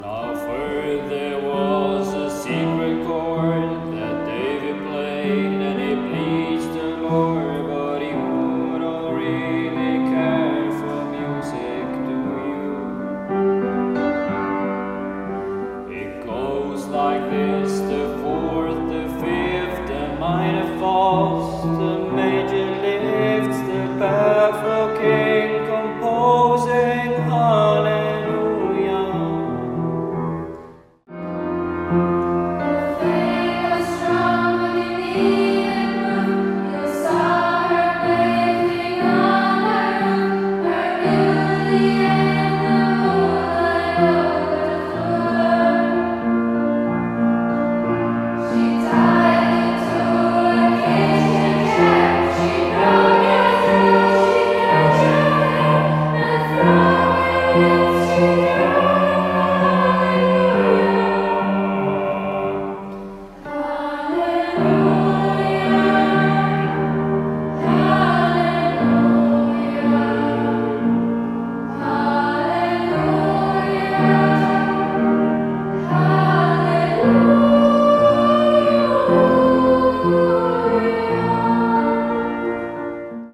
Freifach Chorsingen - Singen ist Trend
Unser Chor singt seit einem Jahr an verschiedenen Anlässen.